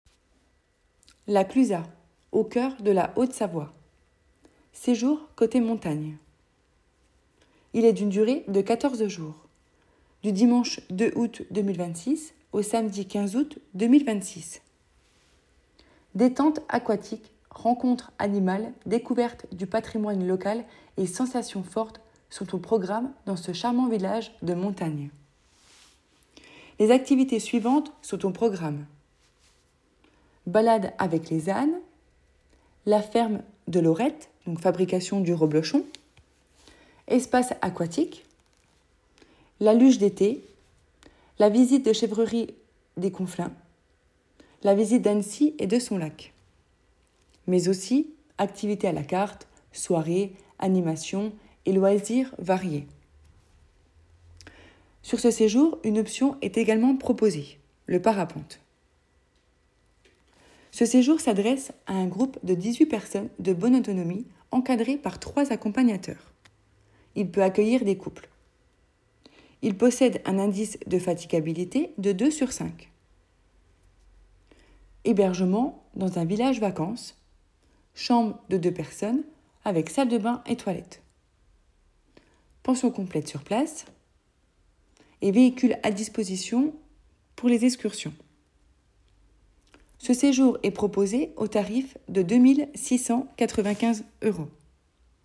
Présentation audio du séjour